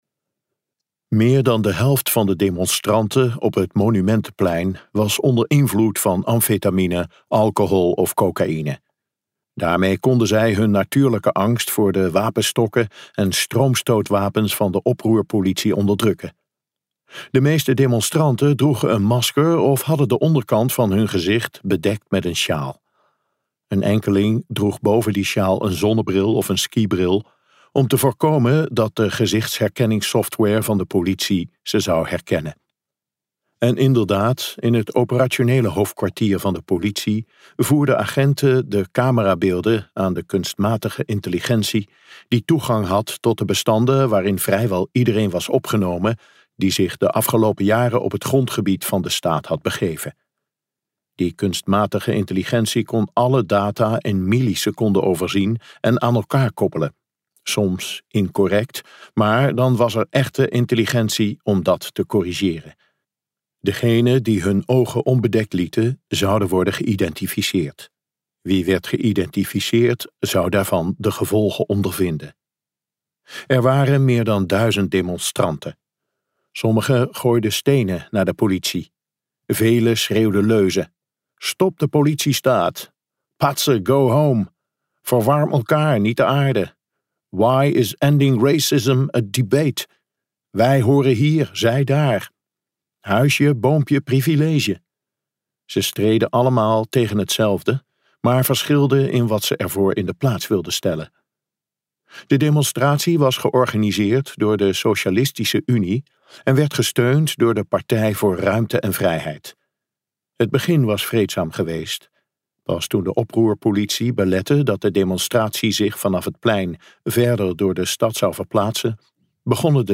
Vandaag is geen dag voor verraad luisterboek | Ambo|Anthos Uitgevers